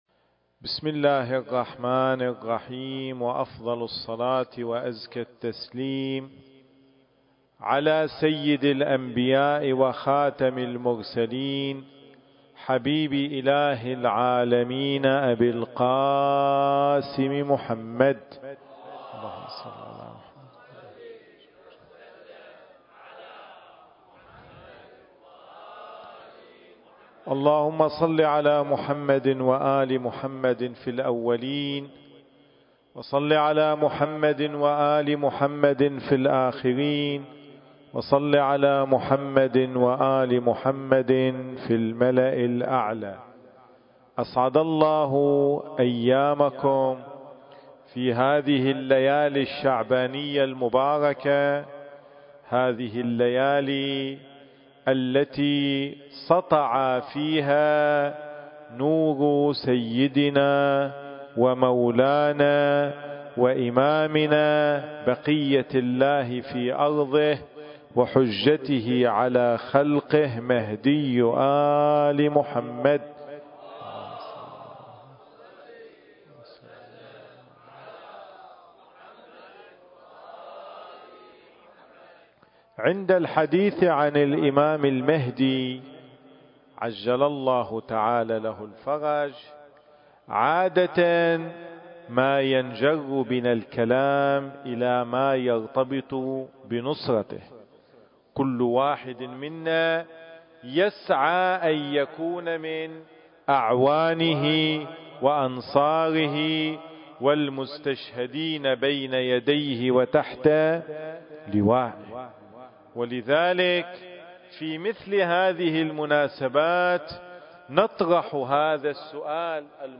المكان: لجنة المعصومين (عليهم السلام) للإحتفالات بالدمام التاريخ: 1442 للهجرة